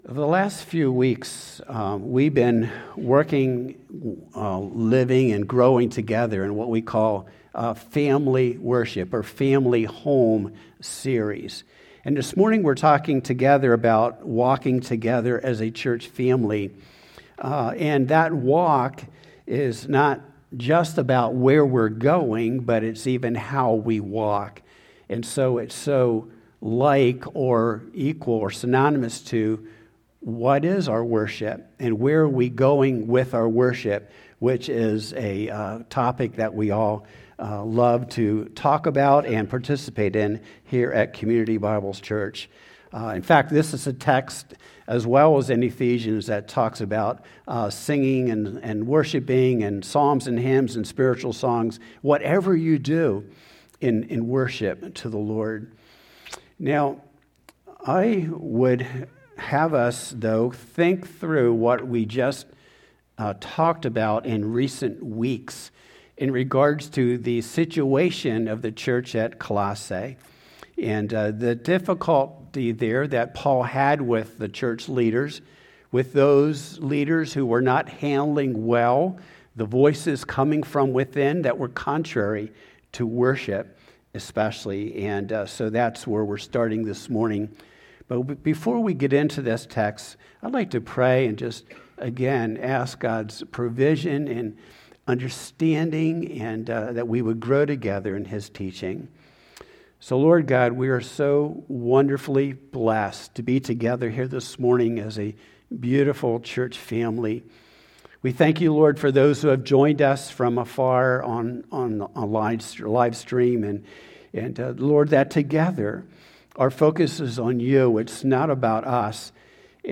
2-15-26-Sermon-Family-Walks.mp3